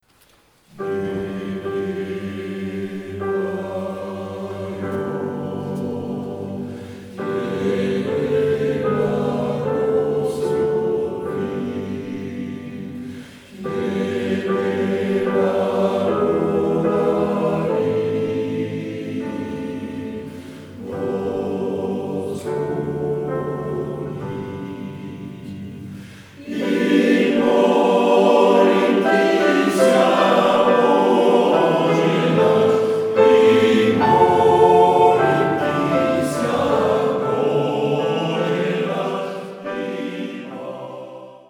Un chœur d’hommes
Selon les œuvres, le choeur chante a cappella ou avec accompagnement instrumental et se produit parfois en partenariat avec d’autres ensembles.